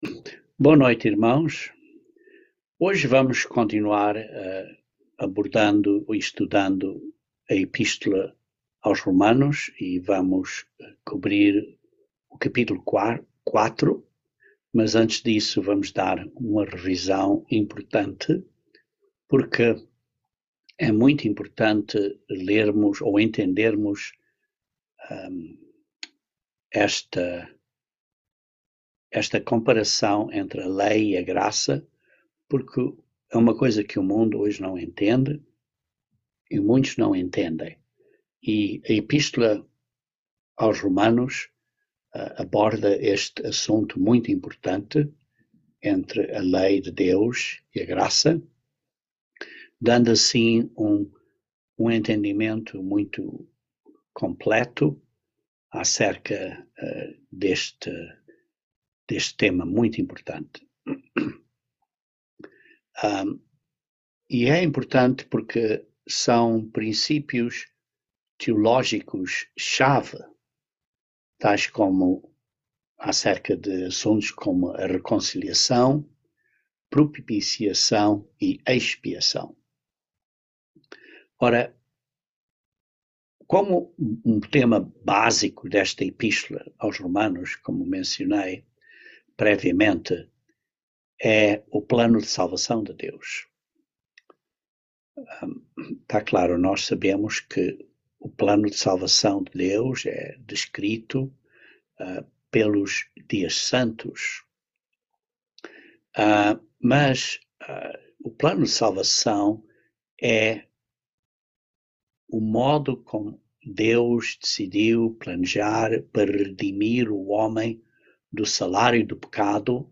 Estudo Bíblico
Given in Patos de Minas, MG